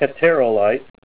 Say HETAEROLITE Help on Synonym: Synonym: ICSD 15305   PDF 24-1133